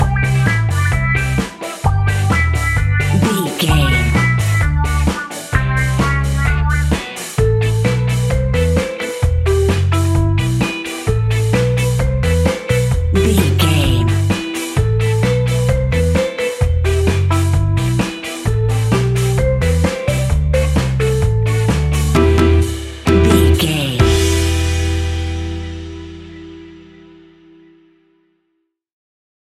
Classic reggae music with that skank bounce reggae feeling.
Aeolian/Minor
reggae
instrumentals
laid back
chilled
off beat
drums
skank guitar
hammond organ
percussion
horns